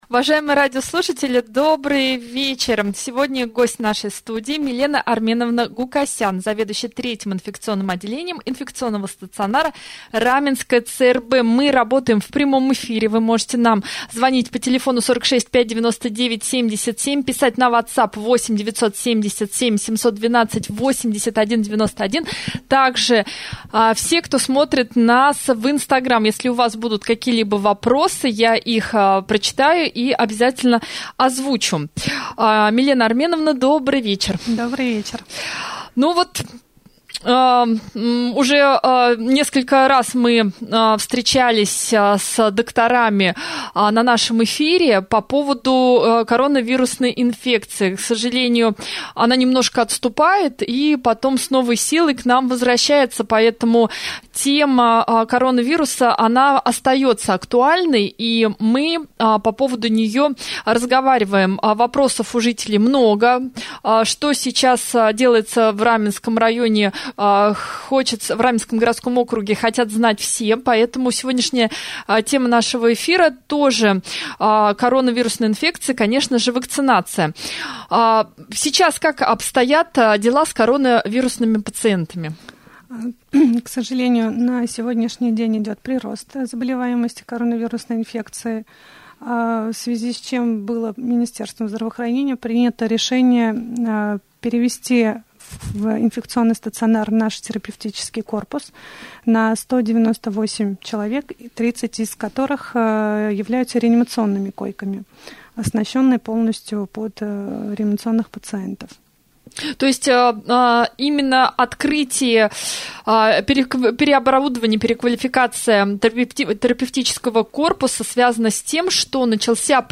prjamoj-jefir-29-ijunja.mp3